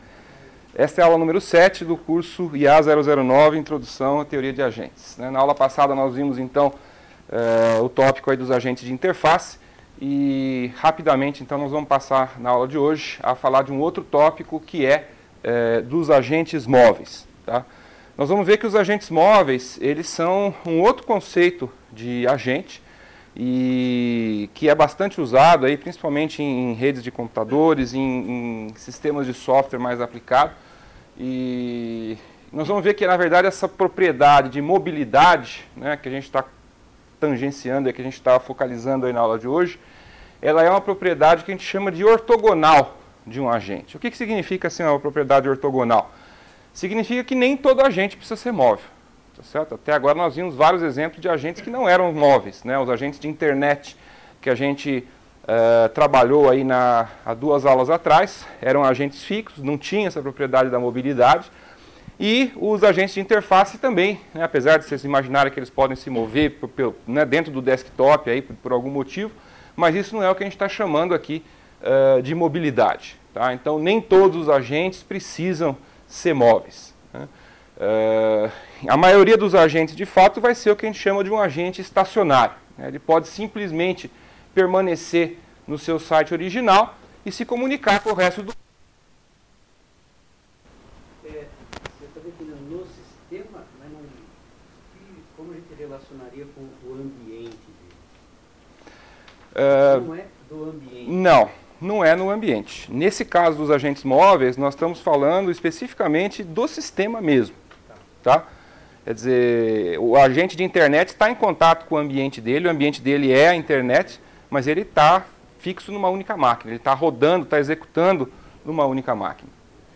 Transpar�ncias da Aula: - Formato PDF - �udio da aula - Formato MP3